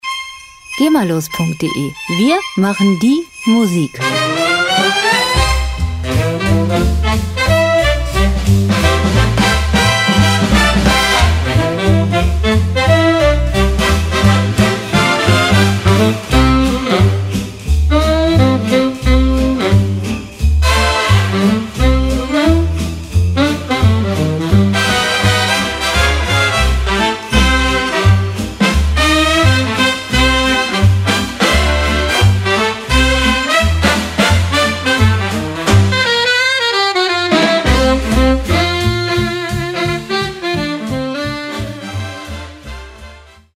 Christmas Jazz & Christmas Swing
Musikstil: Swing
Tempo: 172 bpm
Tonart: C-Dur
Charakter: kraftvoll, dynamisch
Instrumentierung: Big Band